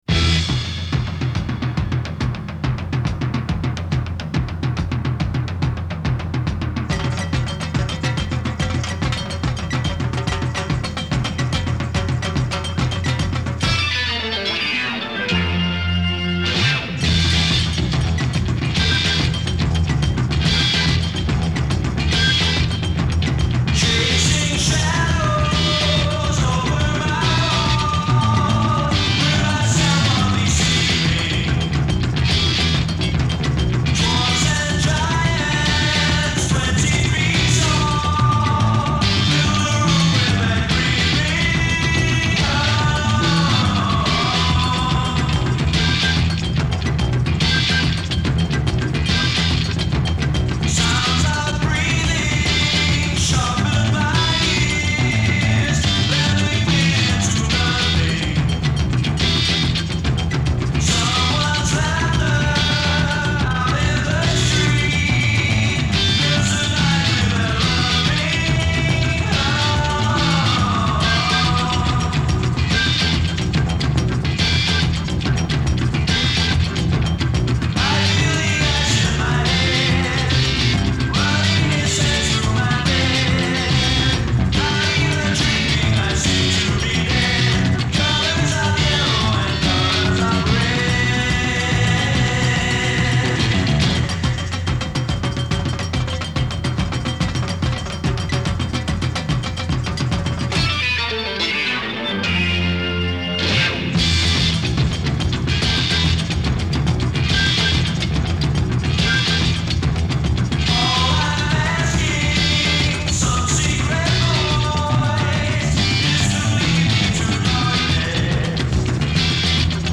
Psychedelic Rock, Progressive Rock, Hard Rock